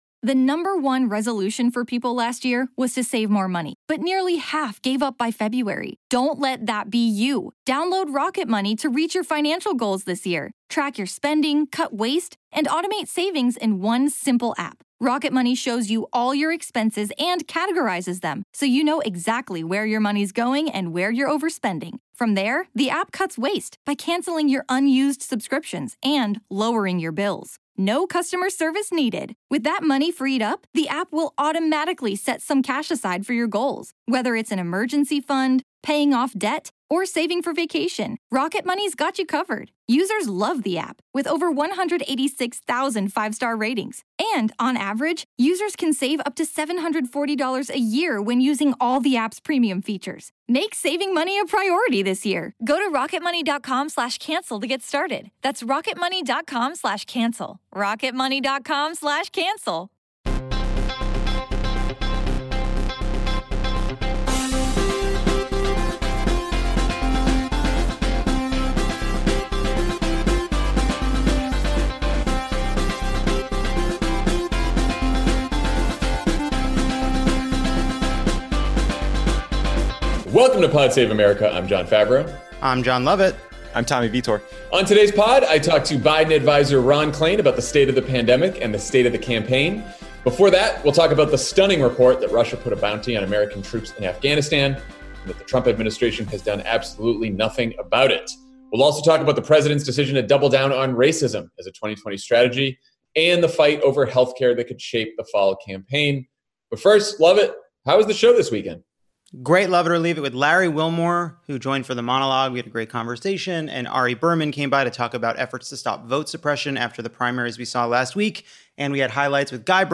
Trump does nothing about reports that Russia put a bounty on American troops, shares a video of his supporters chanting “white power,” and asks the Supreme Court to end the Affordable Care Act. Then Biden adviser Ron Klain talks to Jon Favreau about the state of the pandemic, and the state of the 2020 campaign.